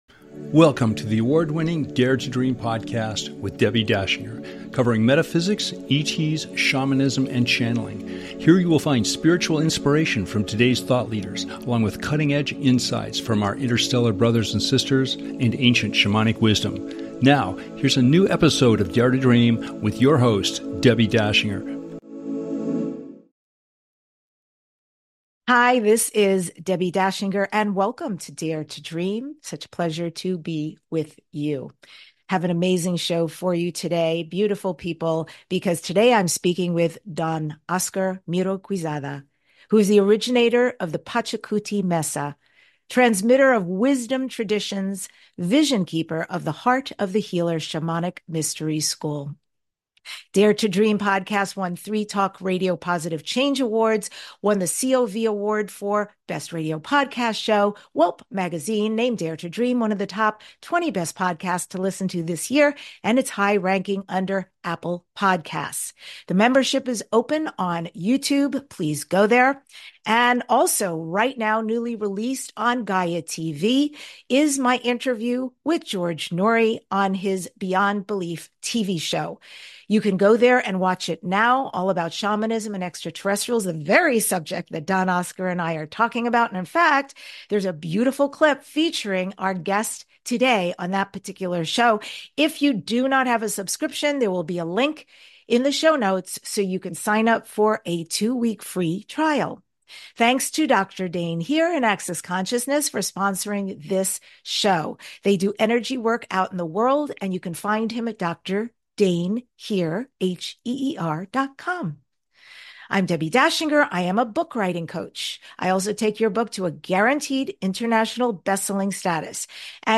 Talk Show Episode
Interview Highlights